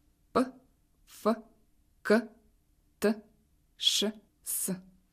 sourdes.ogg